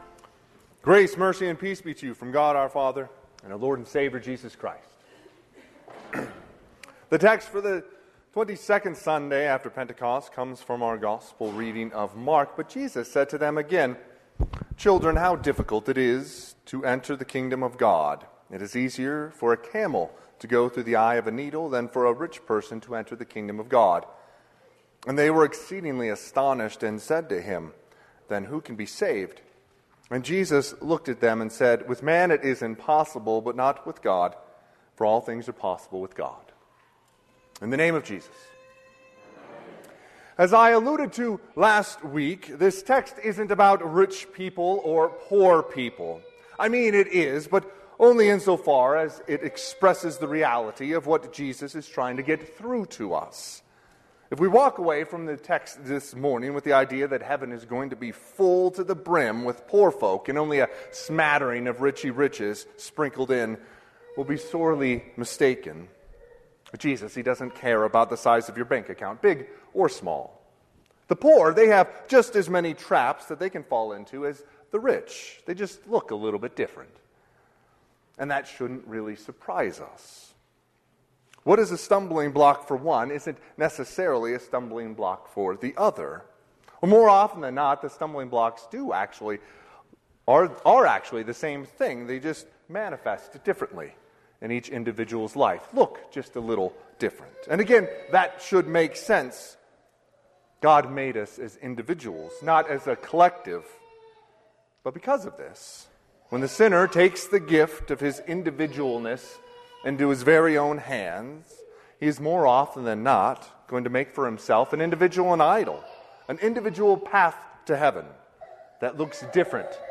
Sermon - 10/20/2024 - Wheat Ridge Lutheran Church, Wheat Ridge, Colorado